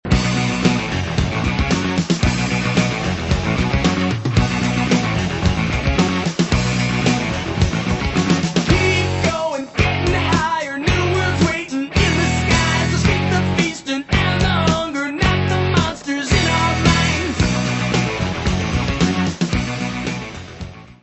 : stereo; 12 cm
Music Category/Genre:  Pop / Rock